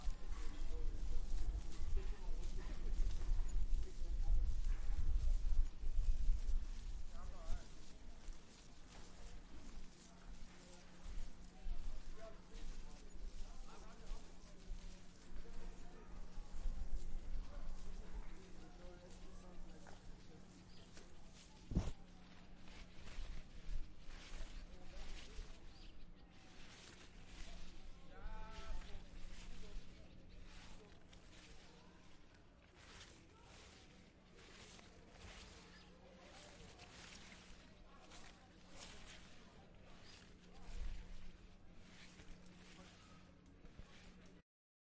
窸窸窣窣的草
描述：草被沙沙作响
Tag: 树叶 沙沙声